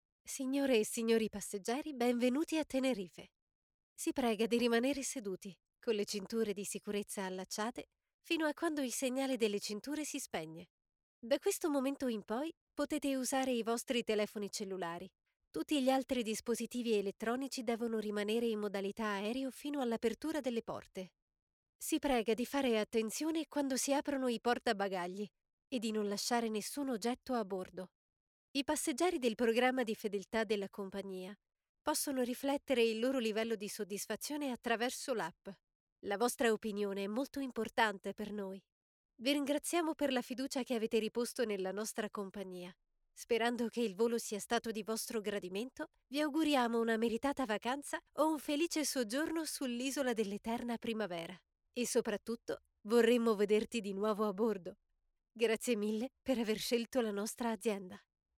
Noted for her fresh, friendly & romantic personas.
Records from her home studio or on location in Geneva and other connected cities throughout Switzerland.
Processing includes light noise removal for mouth clicks and breaths and light compression for simple normalization.
Sprechprobe: Industrie (Muttersprache):
Unprocessed demo_1.mp3